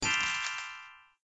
sparkly.ogg